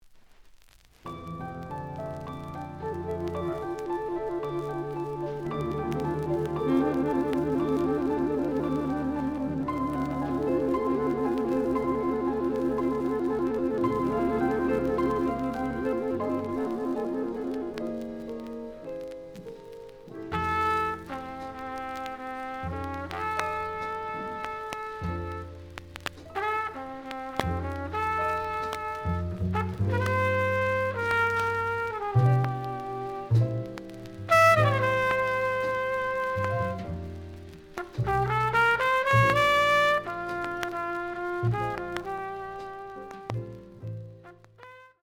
The audio sample is recorded from the actual item.
●Genre: Latin Jazz
Some click noise on B side due to scratches.)